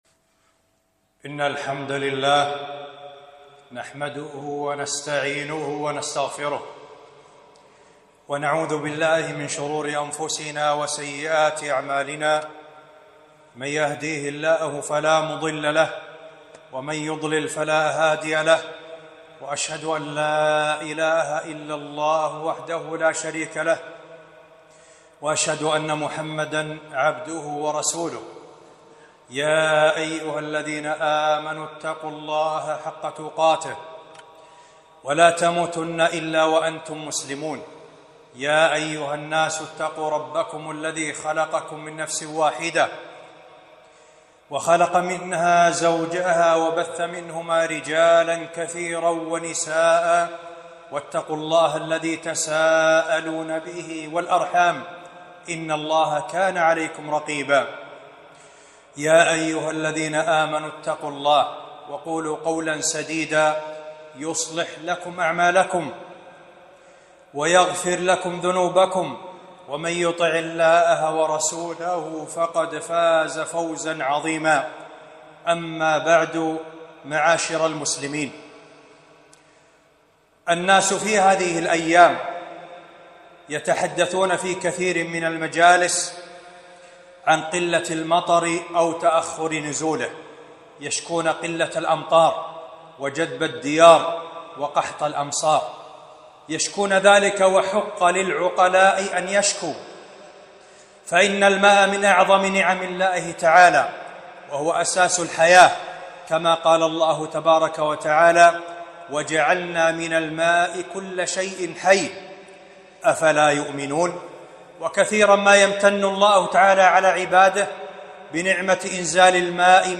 خطبة تأخر نزول المطر